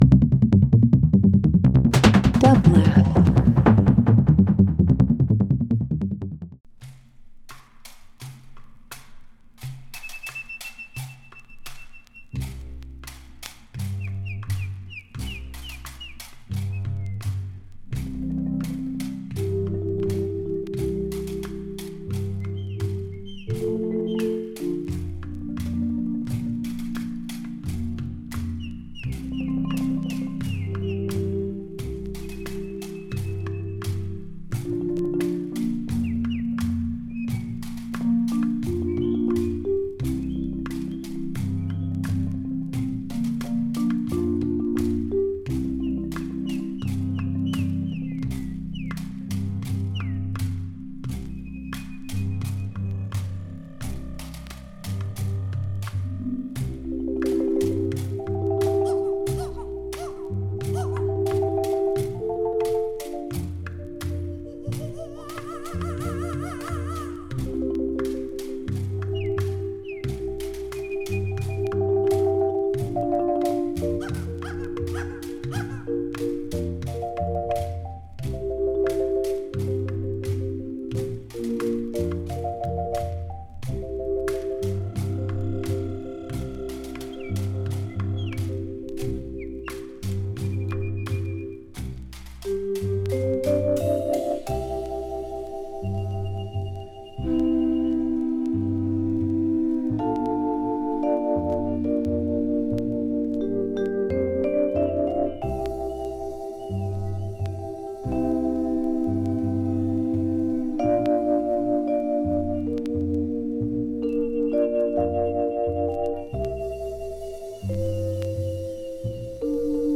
A veteran radio DJ
Funk/Soul Jazz Krautrock Pop
with emphasis on jazz, funk, Hawaiian pop and krautrock.